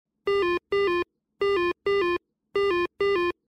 Звуки домофона
Здесь вы найдете как стандартные сигналы вызова, так и редкие варианты – от коротких гудков до мелодичных оповещений.